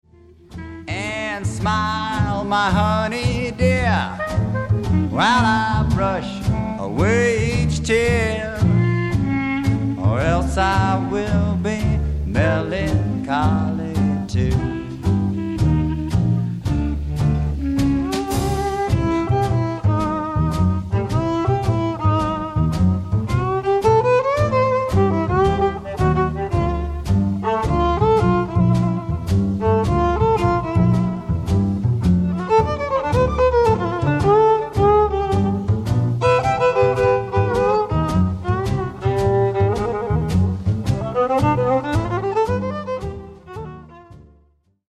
JUG MUSIC / AMERICAN ROOTS MUSIC / BLUES